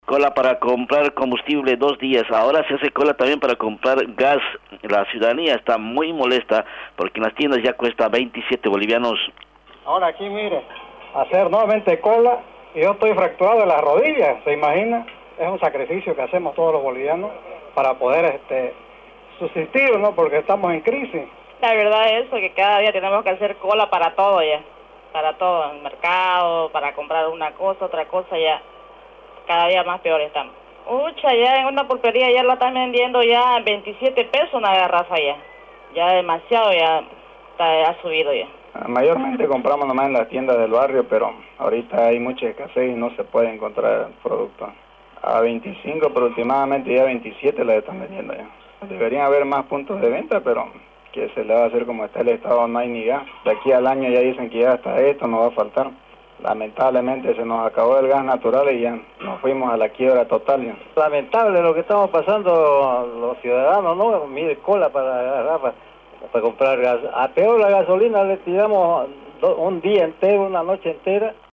Mientras tanto, en las tiendas de barrio de Trinidad, el precio del carburante escaló hasta Bs 27, lo que genera molestia y preocupación entre los vecinos. Un sondeo realizado por Fides Trinidad evidenció el malestar de la población.